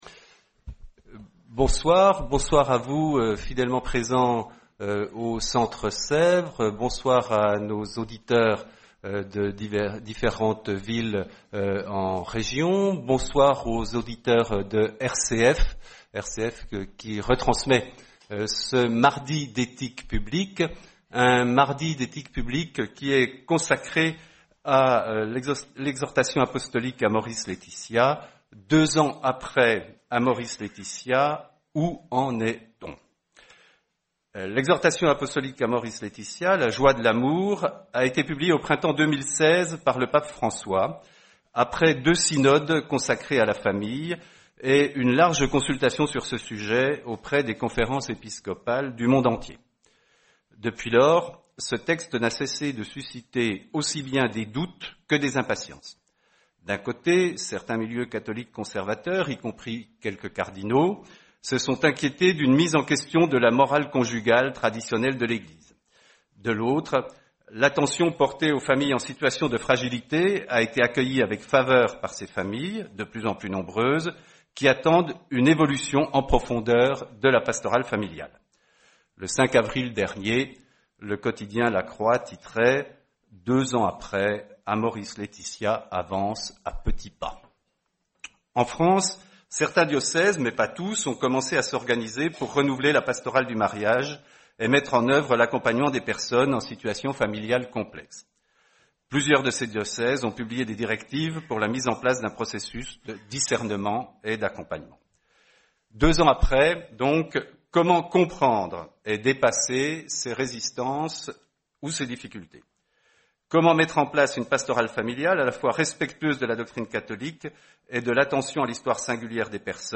Soirée Mardi d’éthique publique du 15 mai 2018, en partenariat avec la revue Études et RCF.